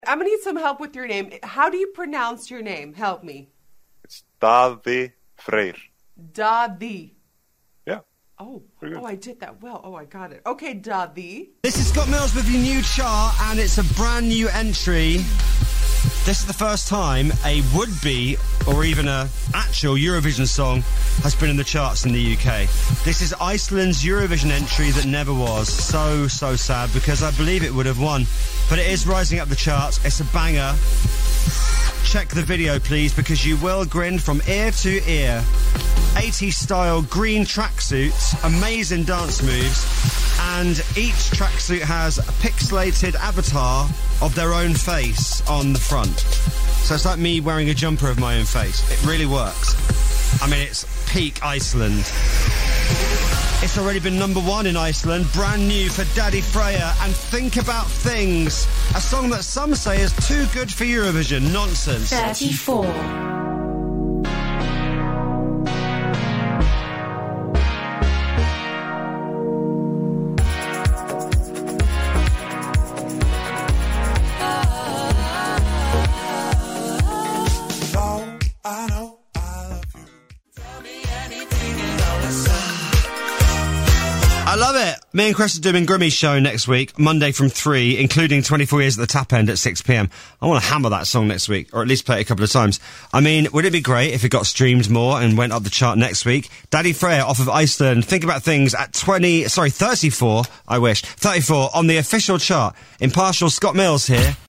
アイスランド出身ドイツ・ベルリン在住のシンガーソングライター